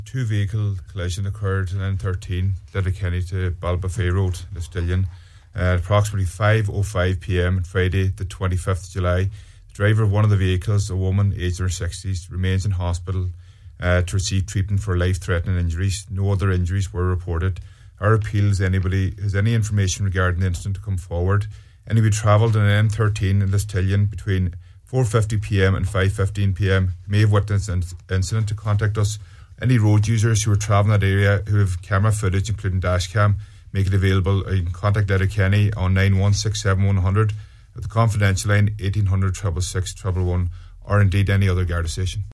Nine ‘Til Noon Show